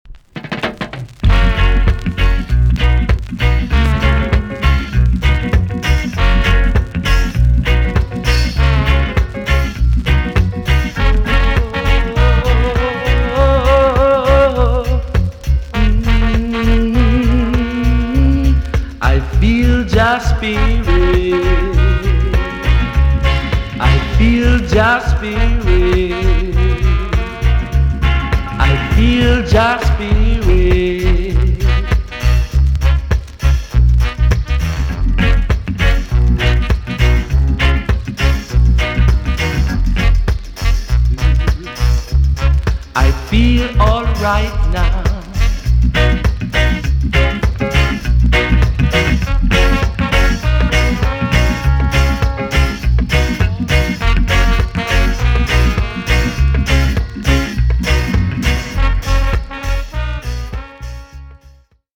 B.SIDE Version
EX-~VG+ 少し軽いチリノイズがありますが良好です。